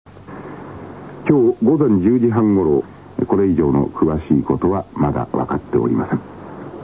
つぎはぎニュース
大昔のラヂヲ番組、タモリのオールナイトニッポンでオンエアされたモノです。
ソースは２０年程前のエアチェックテープです(^^;